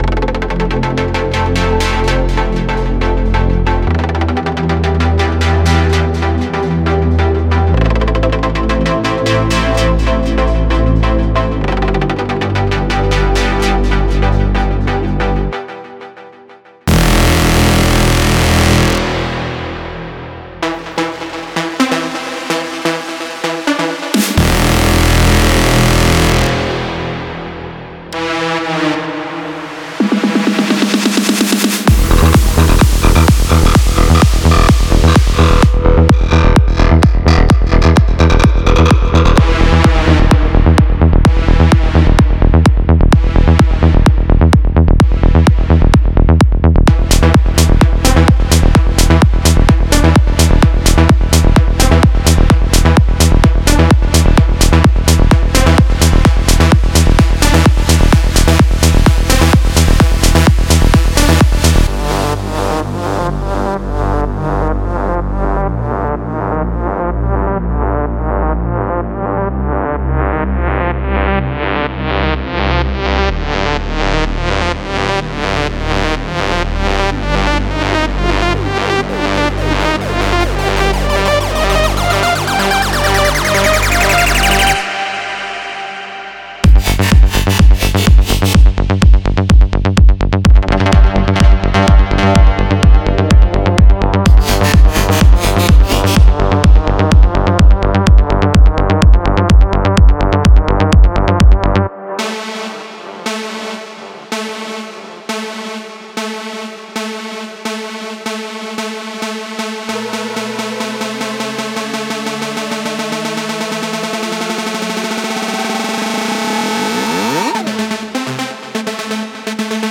デモサウンドはコチラ↓
Genre:Melodic Techno